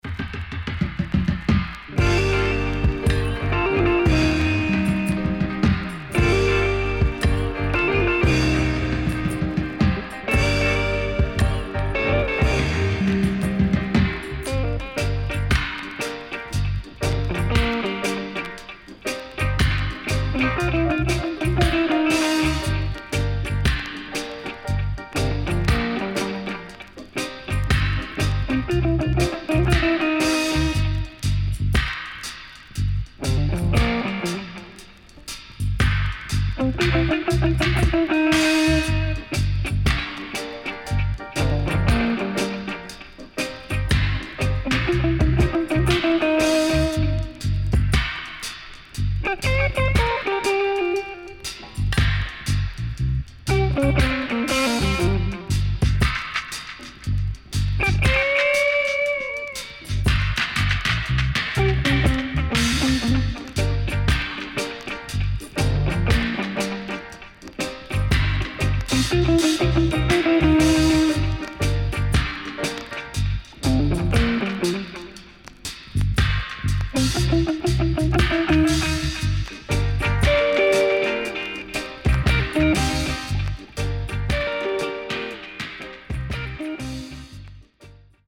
Rare.Great Roots Lovers & Dubwise
SIDE A:うすいこまかい傷ありますがノイズあまり目立ちません。